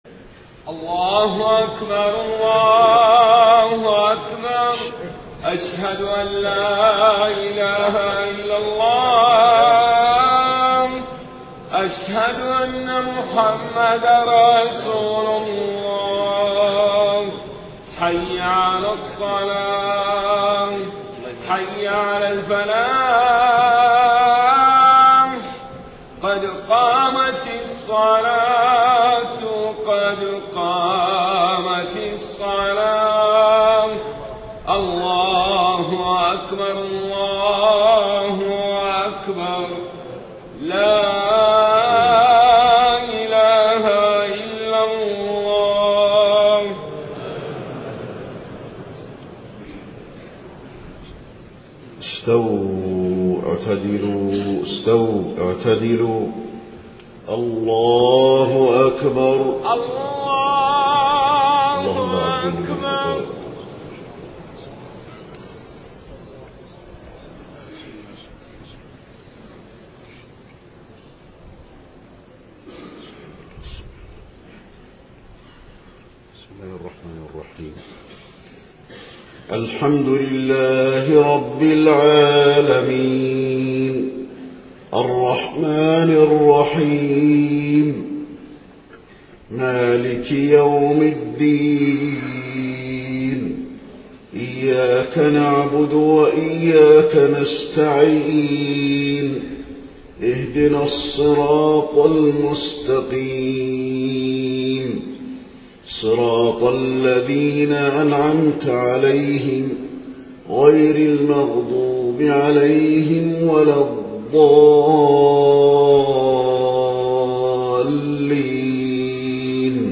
صلاة العشاء 27 محرم 1430هـ سورة الانفطار > 1430 🕌 > الفروض - تلاوات الحرمين